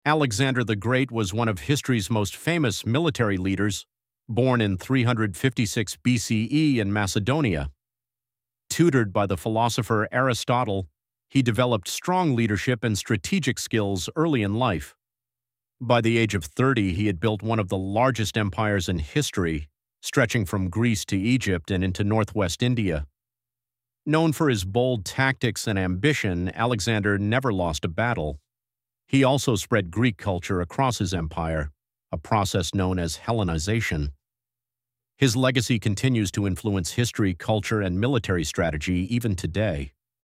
To show what the output looks like, we uploaded an audio narration about Alexander the Great.
The audio was a straightforward voiceover explaining his conquests and legacy.